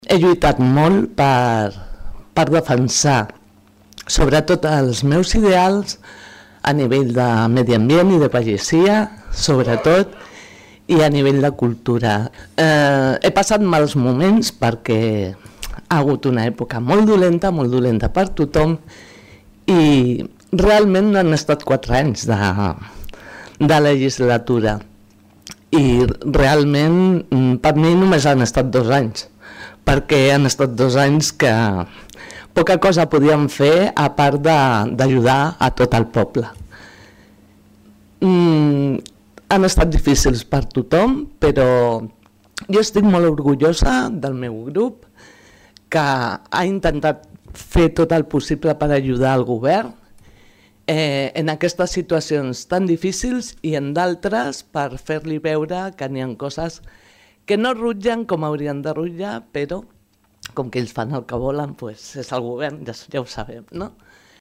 Tots els regidors que plegaven també van realitzar un darrer missatge d’acomiadament.